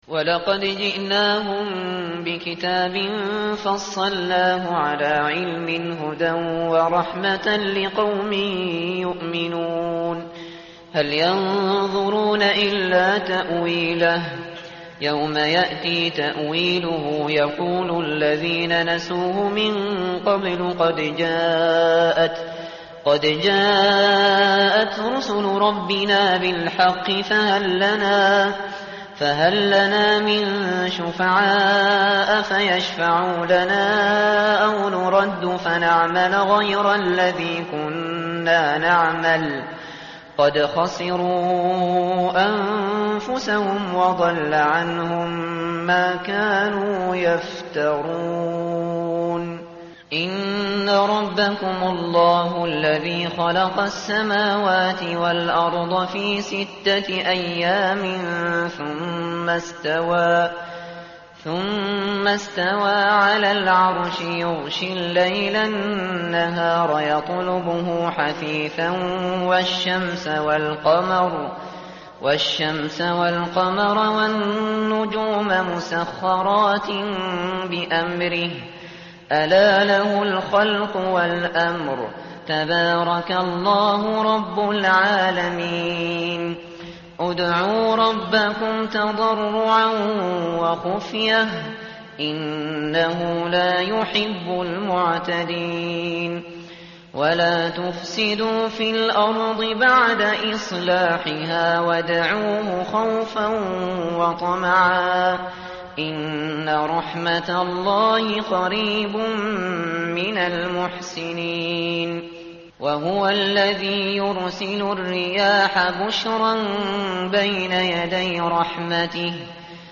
متن قرآن همراه باتلاوت قرآن و ترجمه
tartil_shateri_page_157.mp3